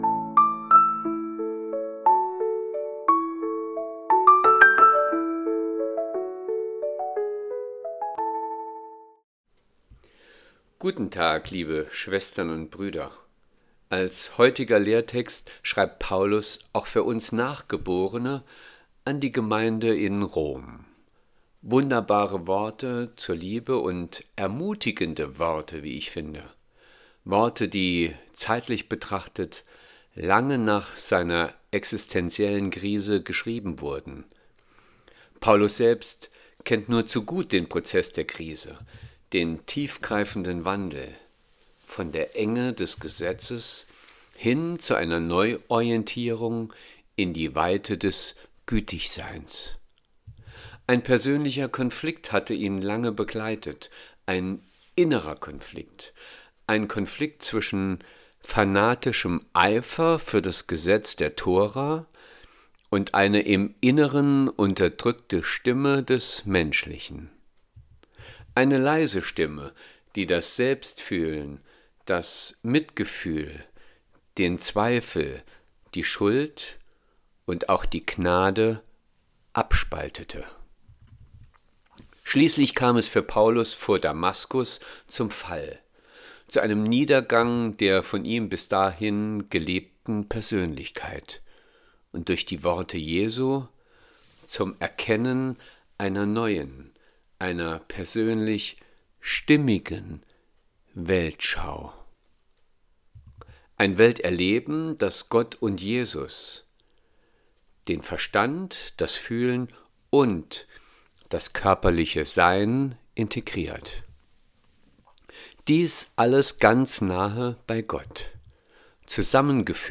Losungsandacht für Mittwoch, 10.09.2025